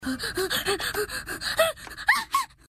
shortbreath
shortbreath.mp3